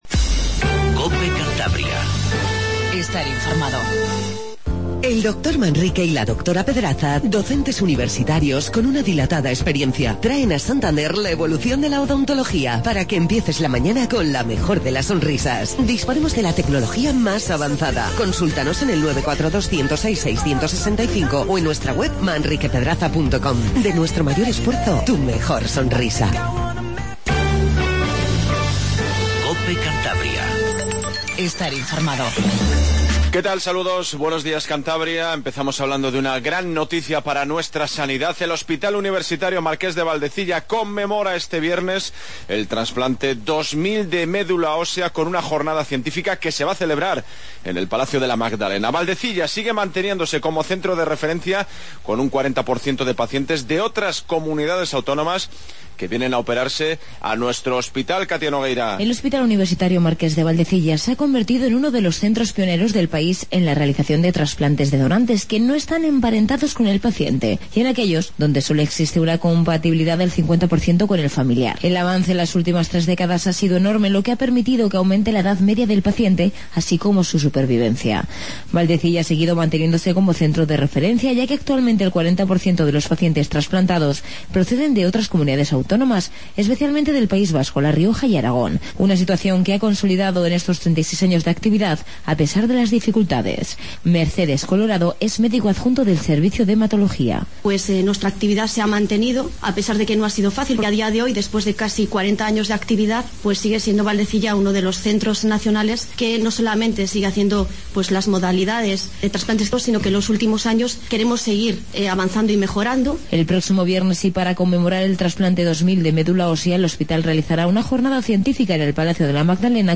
INFORMATIVO MATINAL 07:20